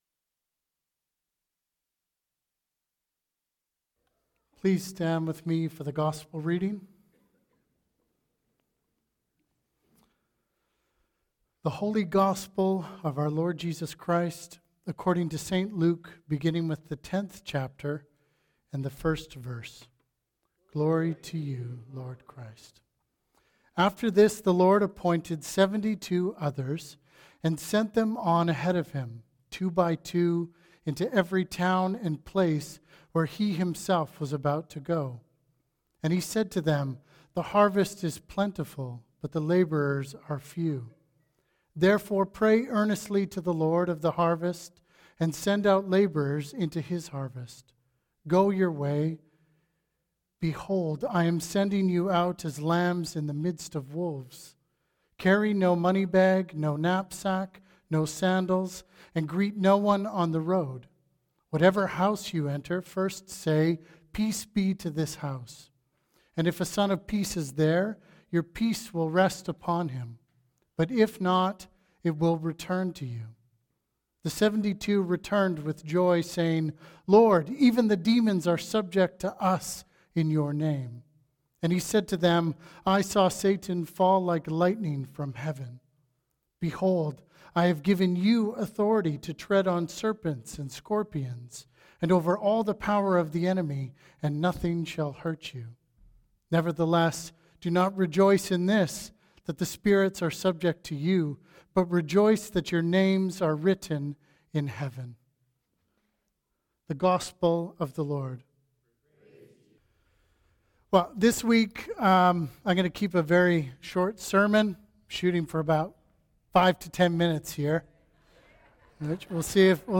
Sermons | Christ's Church Oceanside